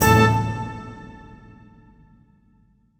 フリー効果音：オーケストラ
オーケストラ風の効果音です！何かのセリフや発表のときの効果音にぴったり！
se_orchestra.mp3